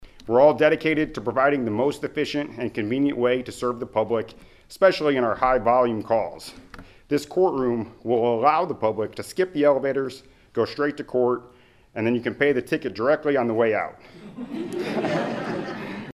Chief Judge Ryan Cadagin said the first-floor courtroom was designed to better accommodate busy dockets: